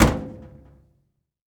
Dryer Door Close Sound
household
Dryer Door Close